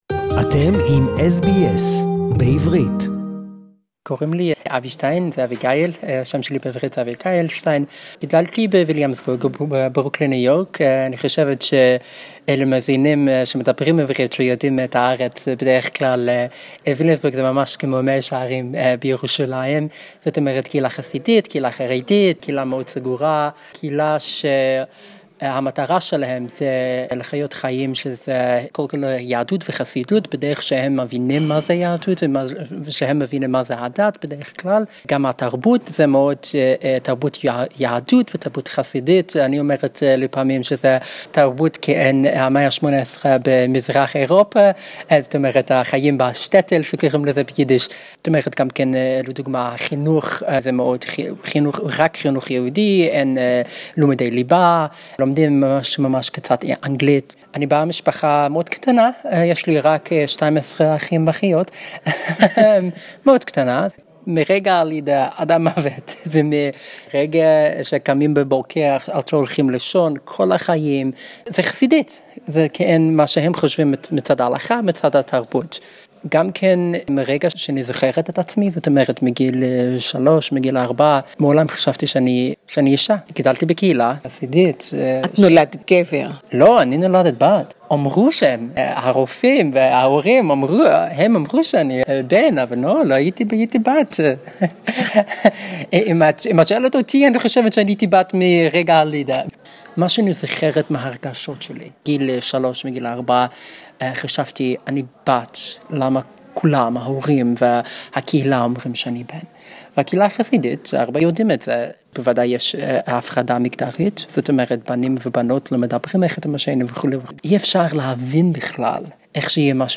I spoke to a very special lady who came from New York to the Mardi Gras two years ago, her name is Abby Stein. Abby told me her remarkable journey from a Hassidic boy to a girl.